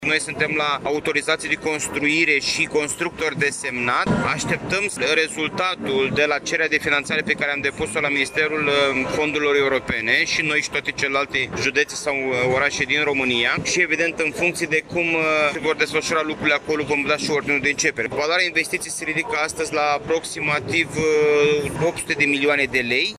Președintele Consiliului Județean, Costel Alexe,  a precizat că se așteaptă rezultatul  de la cererea de finanțare depusă la Ministerul Fondurilor Europene și ulterior va fi emis ordinul de începere a lucrărilor: ”Noi suntem la autorizații de construire și constructor desemnat, așteptăm rezultatul de la cererea de finanțare pe care am depus-o la Ministerul Fondurilor Europene și noi și toate celelalte județe sau orașe din România și, evident, în funcție de cum se vor desfășura lucrurile acolo, vom da și ordinul de începere. Voloarea investiției se ridică, astăzi, la aproximativ 800 de milioane de lei.”